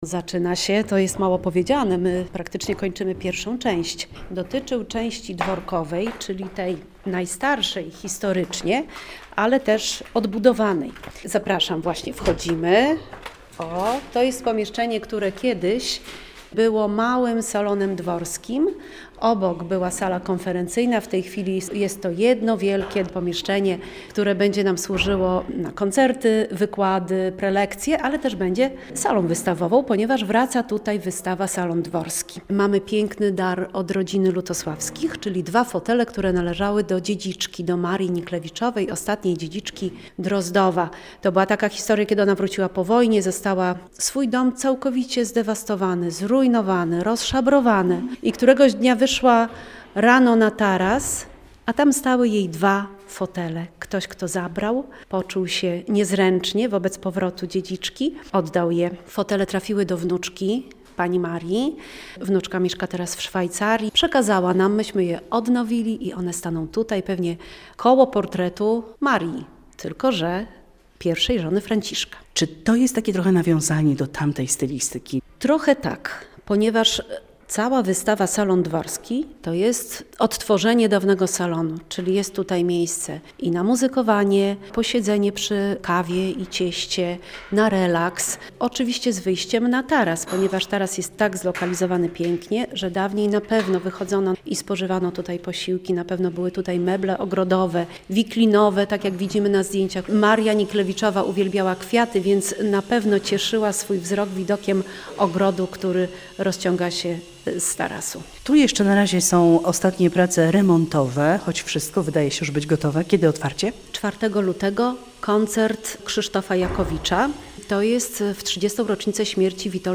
O remoncie Dworku Lutosławskich w rozmowie